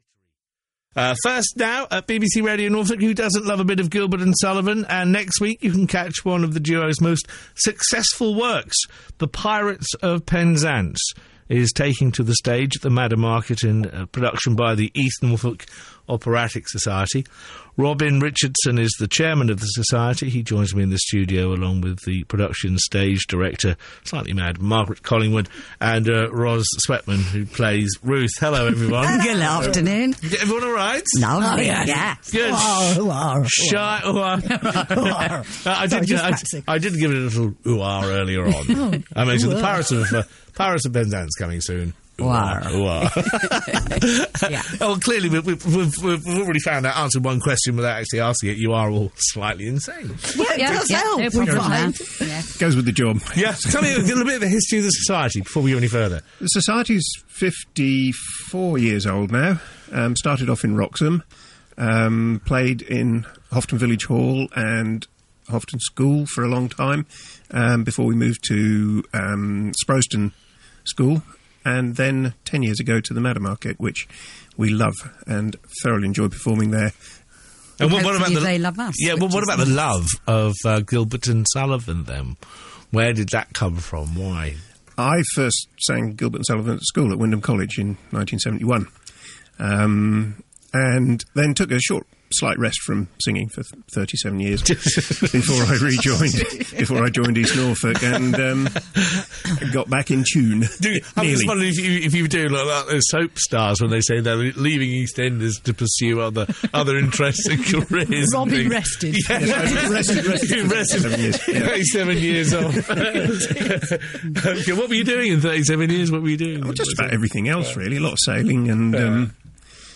Publicity Interview broadcast on BBC Radio Norfolk on 30 April 2013